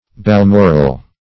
Balmoral \Bal*mor"al\, n. [From Balmoral Castle, in